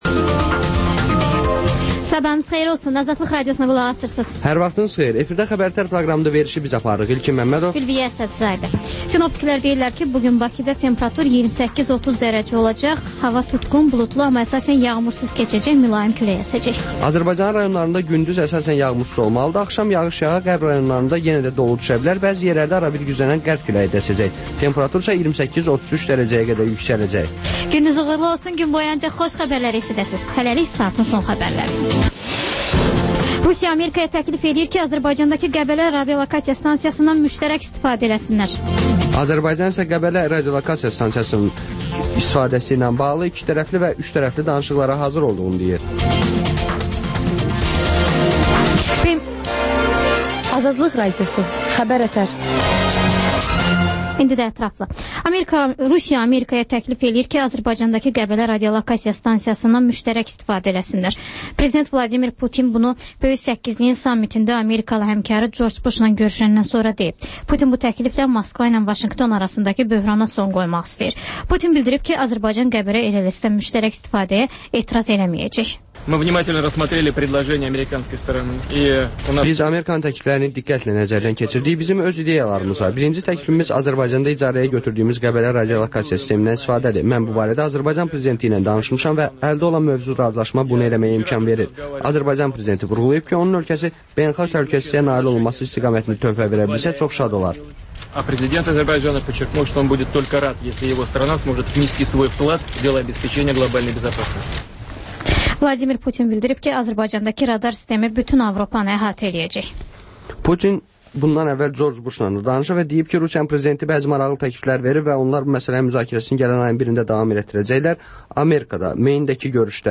Xəbər-ətər: xəbərlər, müsahibələr, sonra 14-24: Gənclər üçün xüsusi veriliş